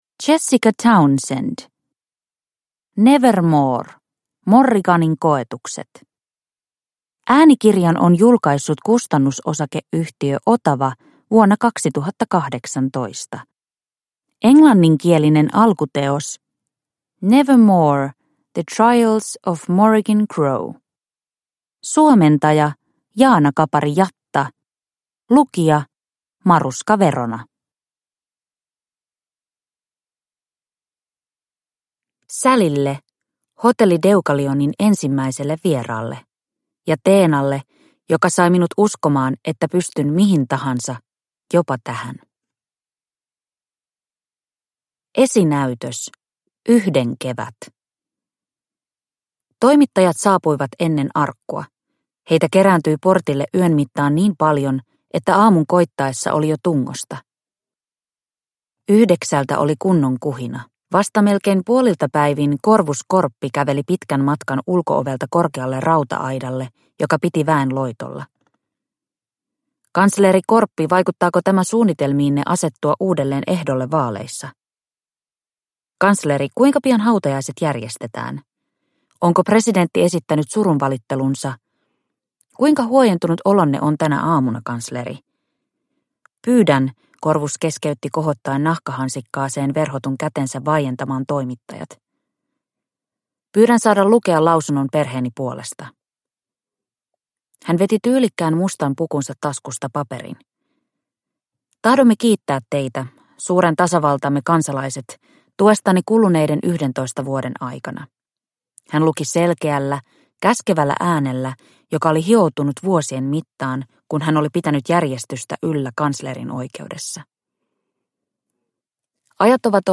Nevermoor - Morriganin koetukset – Ljudbok – Laddas ner